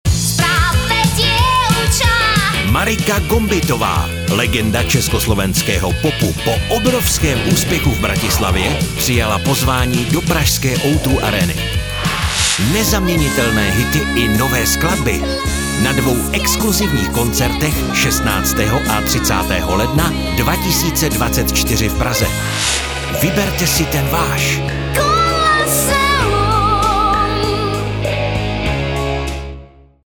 nabízím profesionální, příjemný mužský hlas ošlehaný téměř 30 lety zkušeností u mikrofonů v rádiích i studiích.
Pracuji ve svém nahrávacím studiu nebo po dohodě kdekoliv jinde.